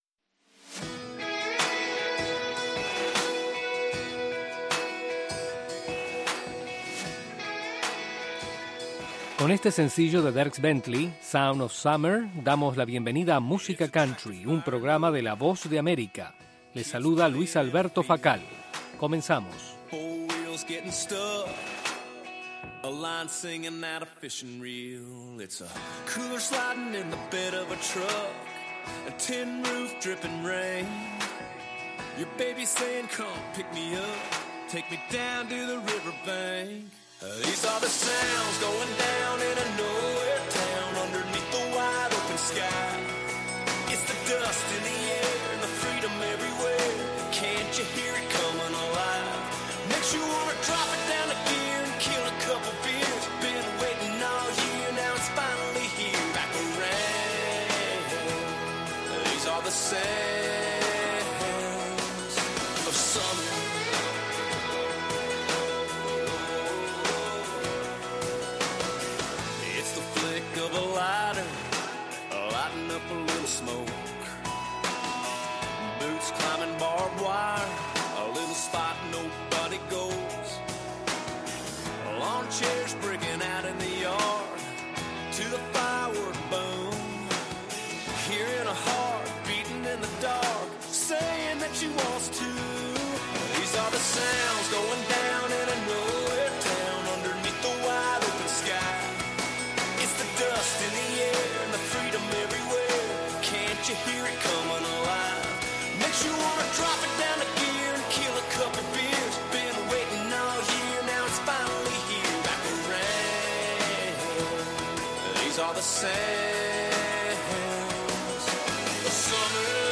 Música Country